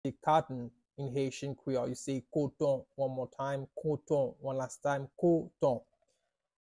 “Cotton” in Haitian Creole – “Koton” pronunciation by a native Haitian Creole tutor
“Koton” Pronunciation in Haitian Creole by a native Haitian can be heard in the audio here or in the video below:
How-to-say-Cotton-in-Haitian-Creole-–-Koton-pronunciation-by-a-native-Haitian-Creole-tutor.mp3